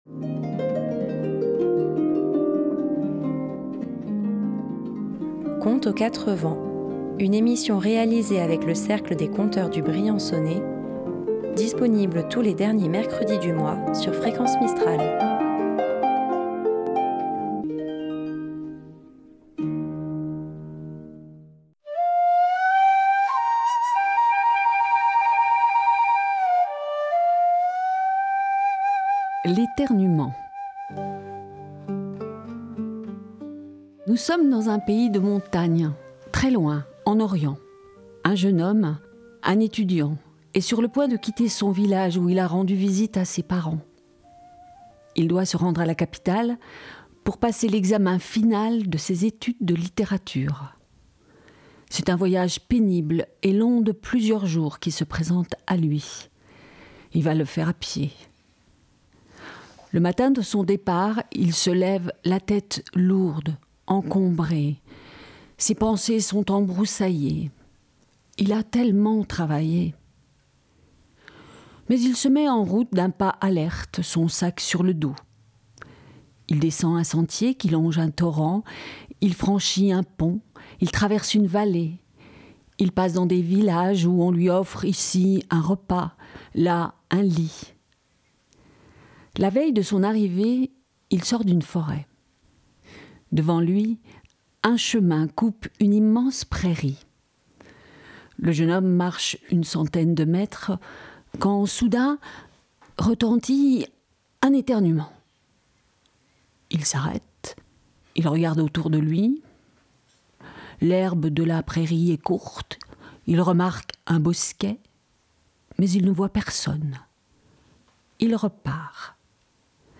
Tous les derniers mercredi du mois, à 18h10, retrouvez le Cercle des conteurs du Briançonnais pour une balade rêveuse.